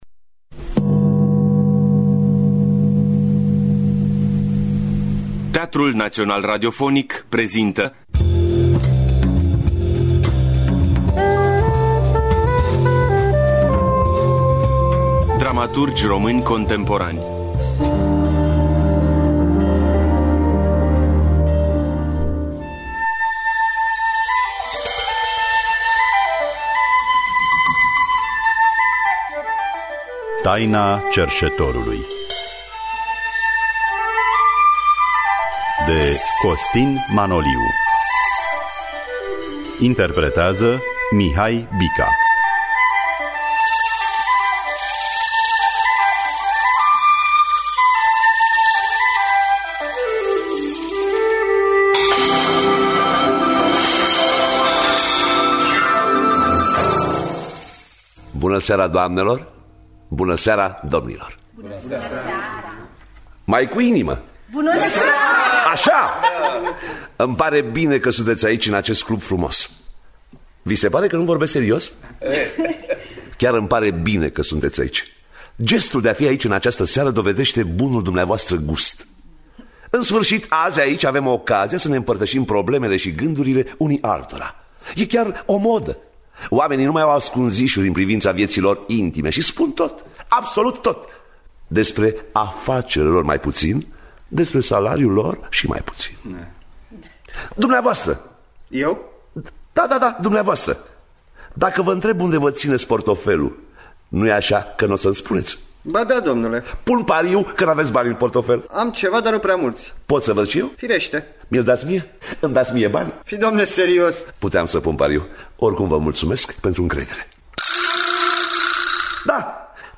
Adaptarea radiofonică
saxofon şi flaut
percuţie
pian